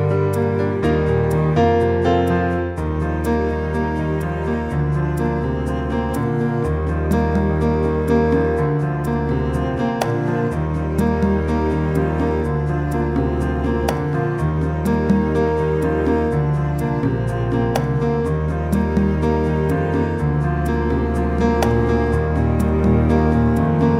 2 Semitones Down